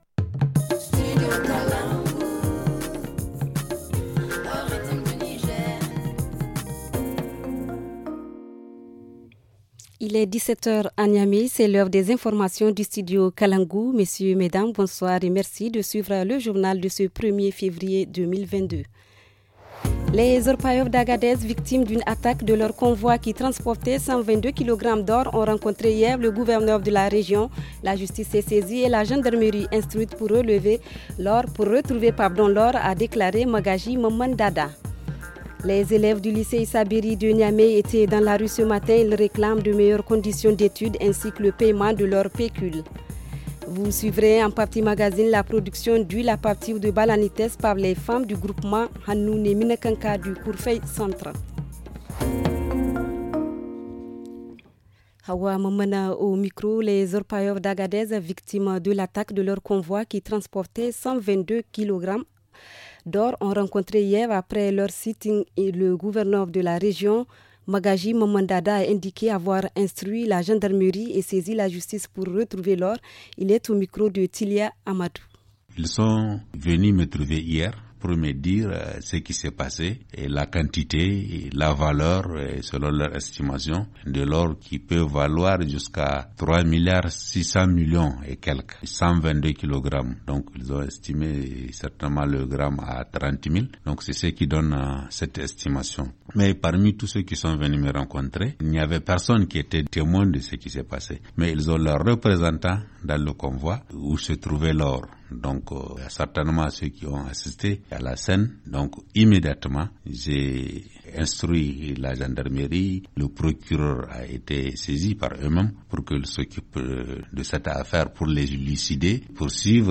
Le journal du 1er février 2022 - Studio Kalangou - Au rythme du Niger